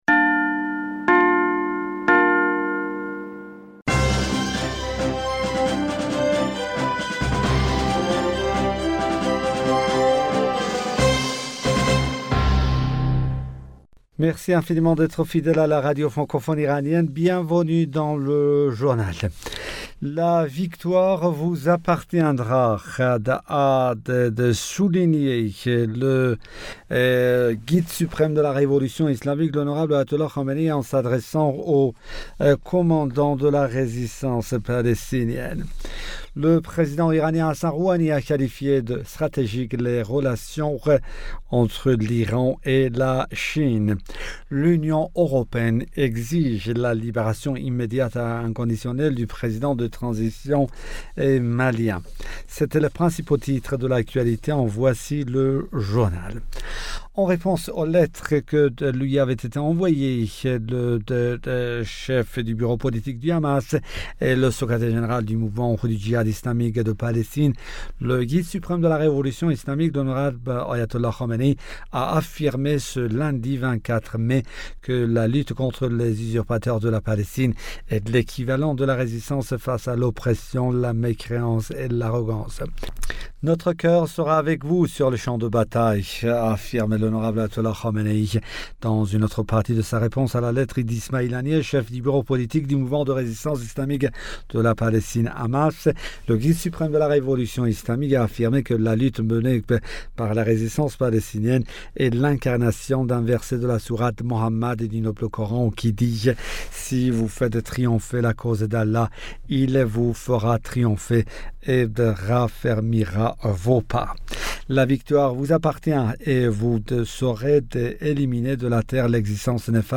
Bulletin d'information du 25 mai 2021